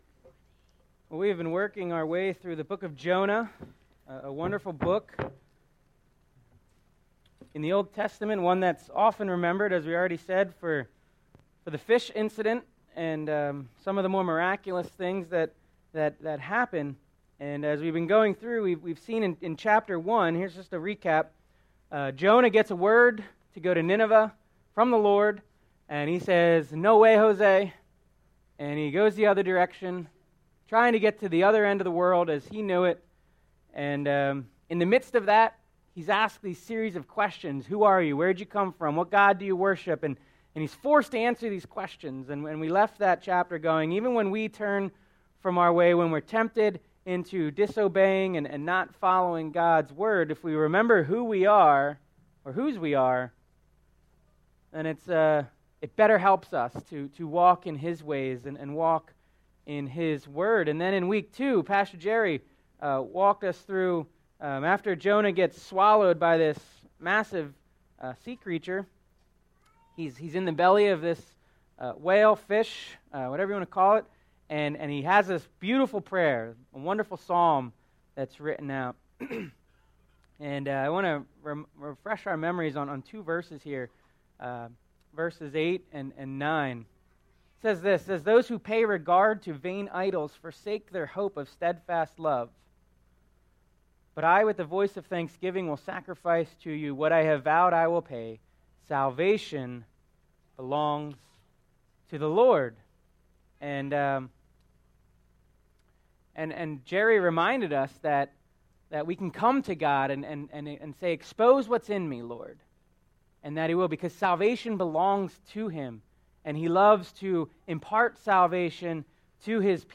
Sermon Series - May 22 - Going Our Way May 29 - Forsaking Our Way June 5 - My Story - Tesimony of God's Relentless Love June 12 - Going God's Way June 19 - Trusting God's Way